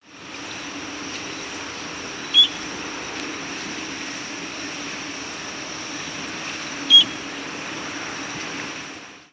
Rose-breasted Grosbeak diurnal
Rose-breasted Grosbeak diurnal flight calls